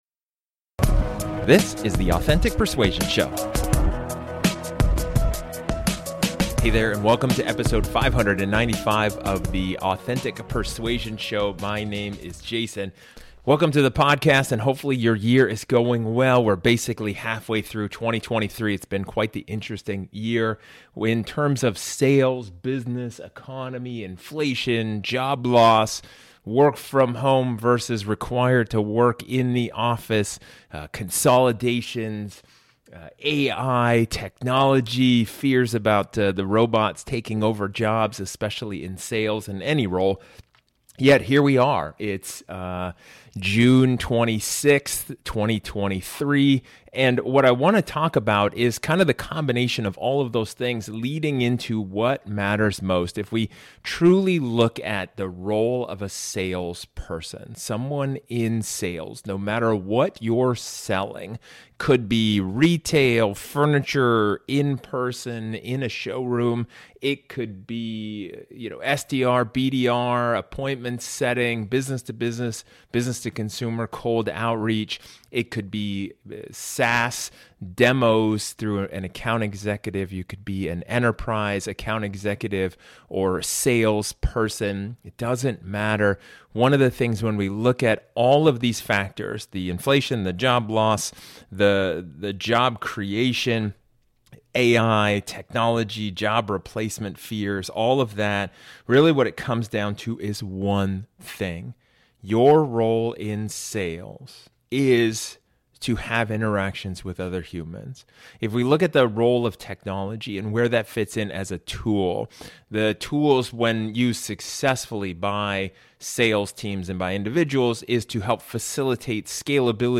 Your sales process and your customer's sales experience should be built around that. In this solo episode, I talk about the one job that AI can't replace.